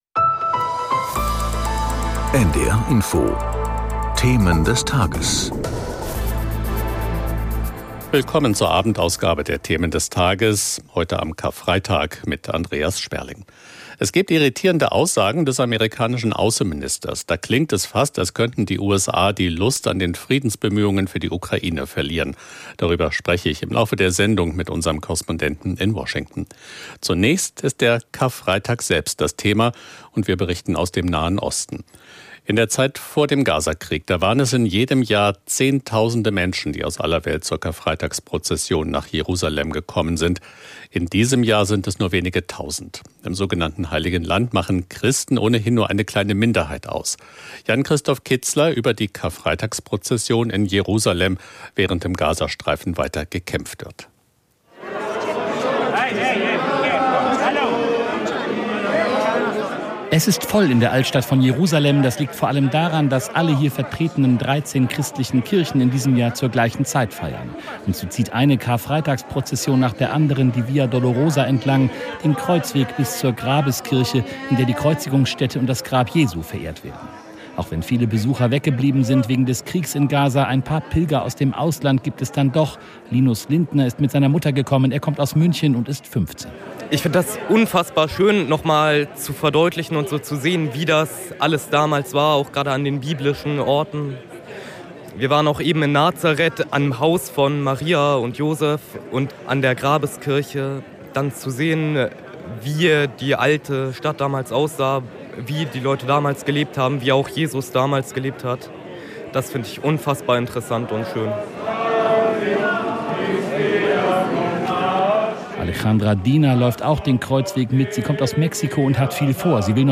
In Interviews mit Korrespondenten, Experten oder Politikern.